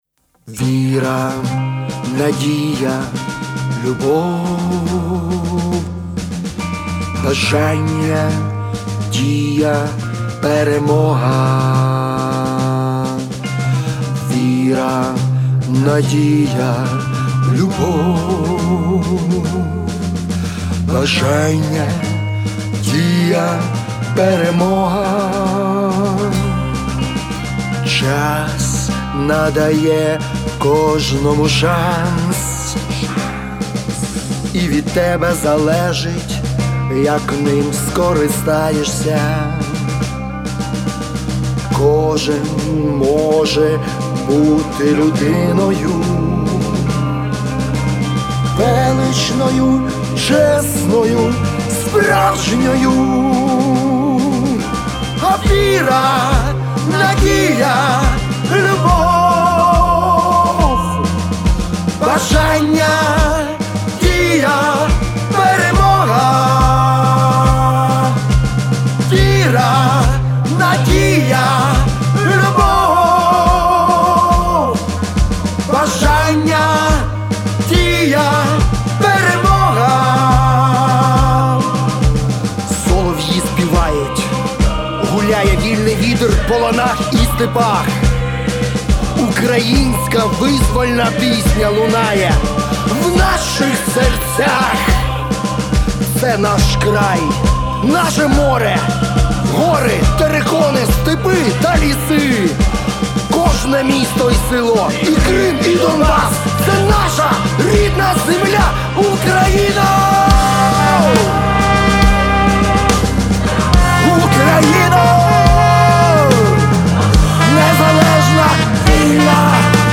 естонсько-український фолк-рок-гурт